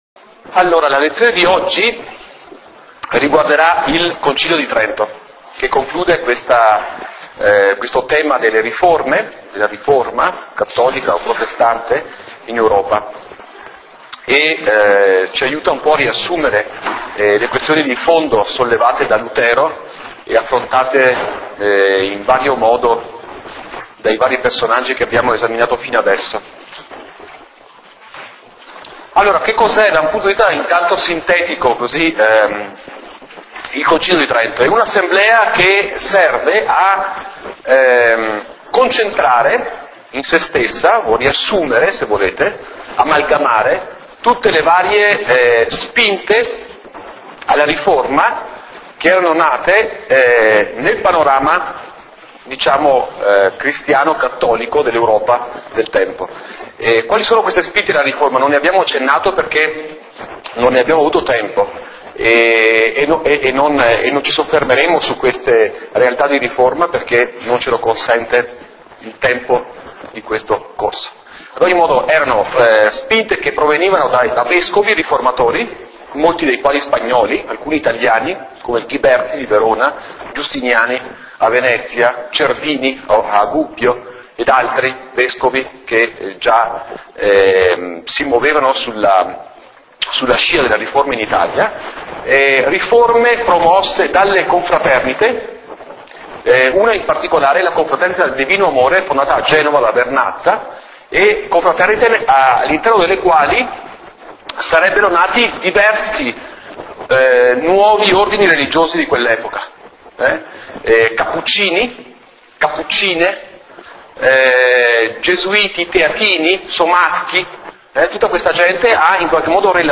11� Lezione: Concilio di Trento